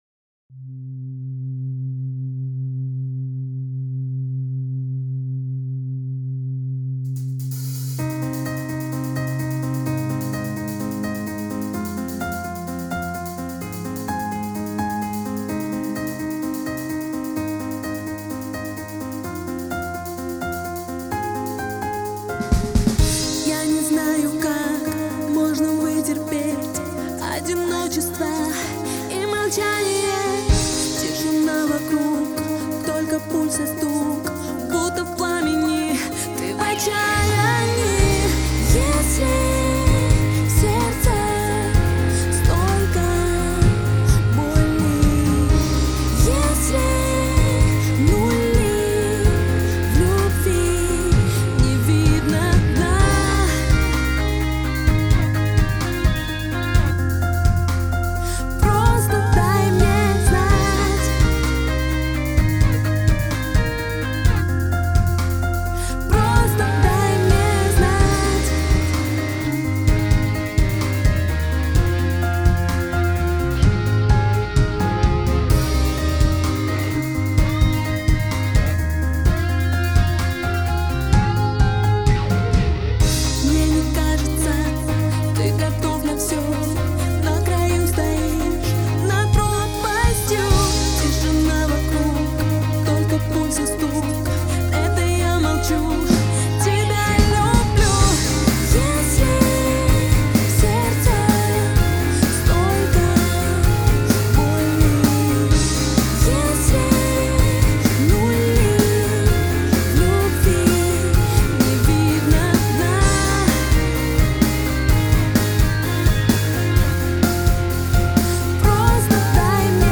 Страдаем...с надрывом